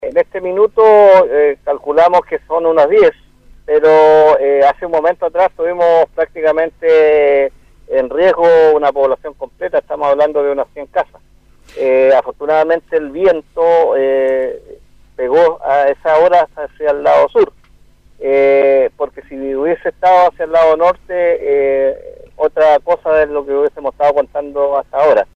En conversación con Radio Bío Bío de Valdivia, el alcalde Juan Rocha detalló que el fuego destruyó vegetación perteneciente a una empresa forestal y amenazó a una población completa.
cuna-alcalde-rocha.mp3